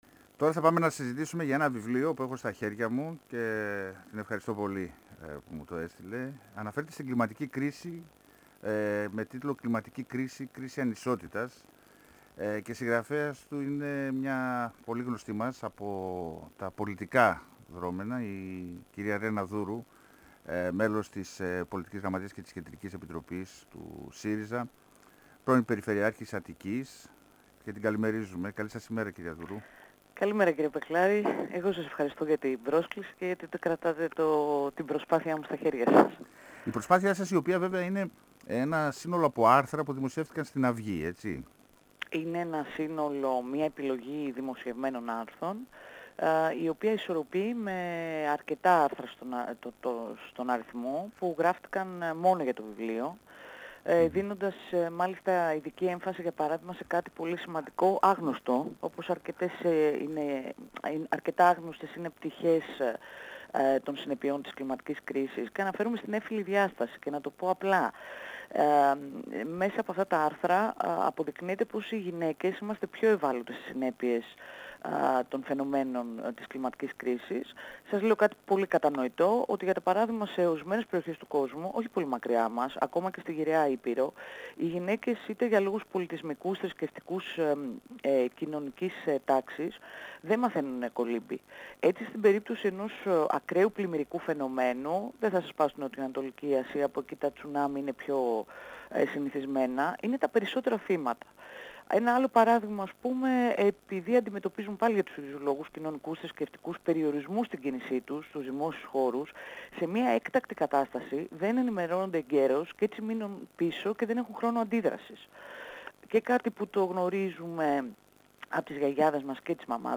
Για το βιβλίο της Κλιματική Κρίση – Κρίση Ανισότητας μίλησε στον 102fm η Ρένα Δούρου μέλος της εκτελεστικής γραμματείας του ΣΥΡΙΖΑ.
Για το βιβλίο της Κλιματική Κρίση – Κρίση Ανισότητας μίλησε στον 102fm η Ρένα Δούρου μέλος της εκτελεστικής γραμματείας του ΣΥΡΙΖΑ. 102FM Συνεντεύξεις ΕΡΤ3